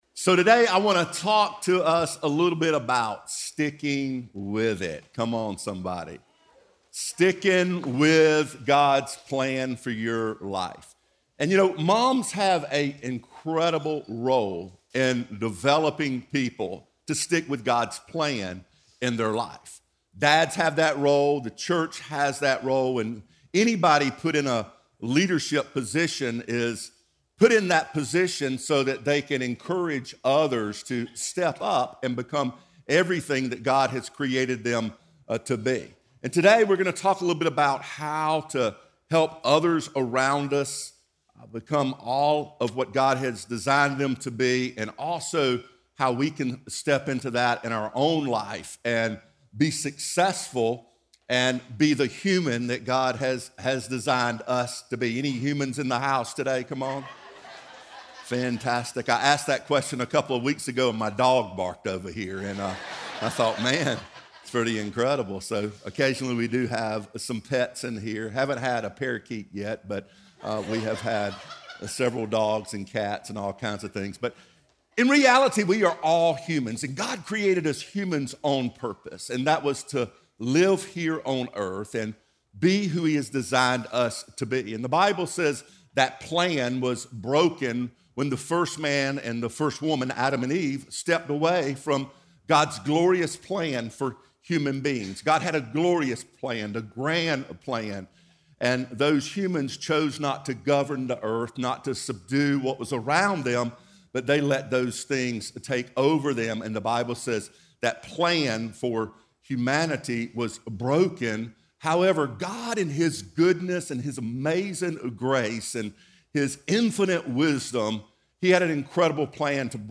a dynamic, high-energy speaker